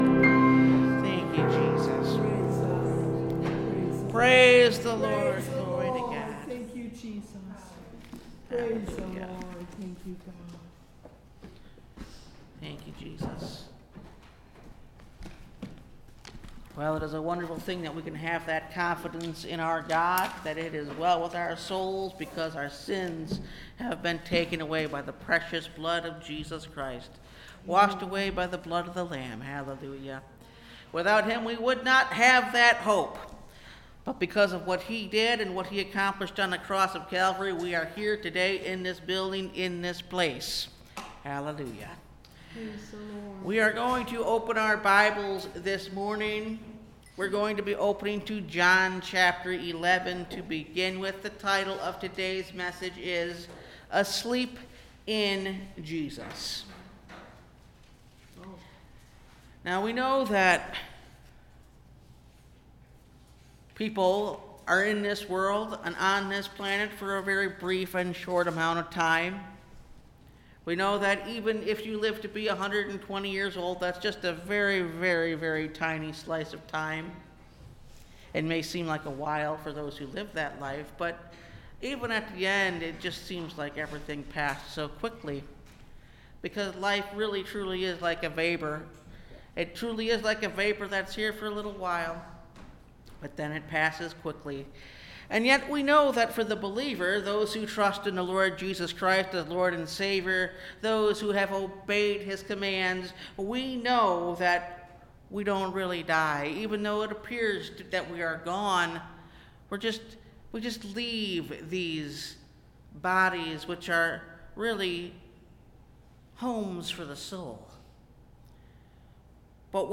Asleep In Jesus (Message Audio) – Last Trumpet Ministries – Truth Tabernacle – Sermon Library